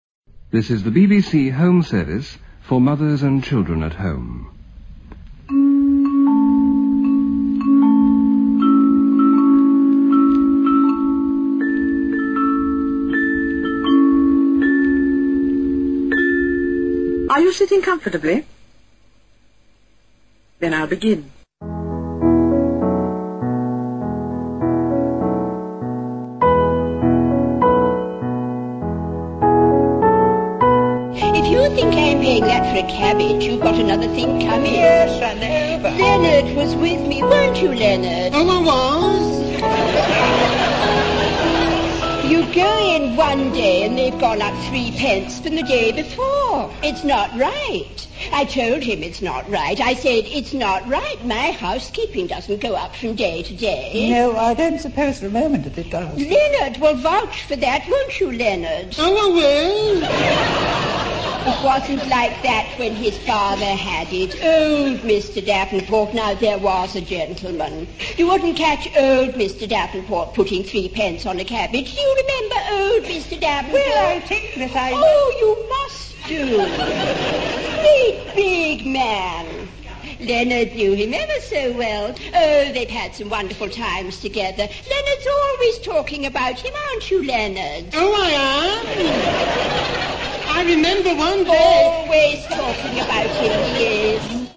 On radio, her voice, redolent of an era, was heard in ‘The Clitheroe Kid’. From 1950 to 1971, she appeared on ‘Listen with Mother’, telling stories which kept her young audience seen and not heard.